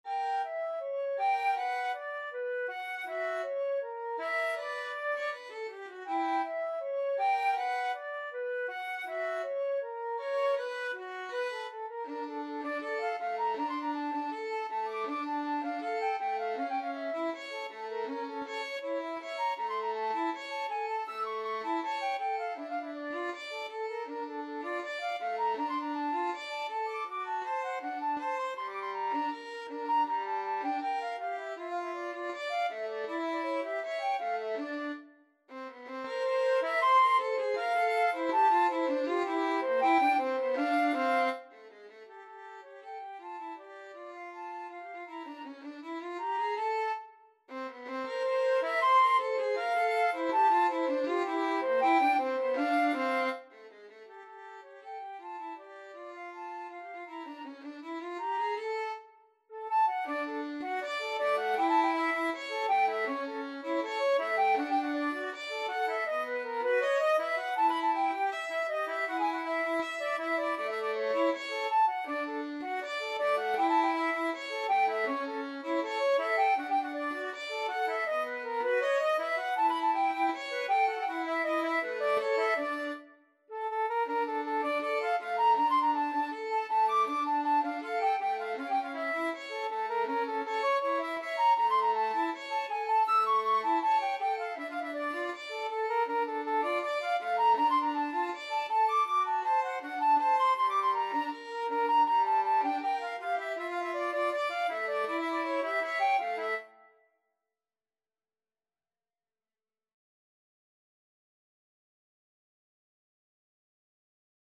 Free Sheet music for Violin-Flute Duet
FluteViolin
D major (Sounding Pitch) (View more D major Music for Violin-Flute Duet )
Steadily = c.80
2/4 (View more 2/4 Music)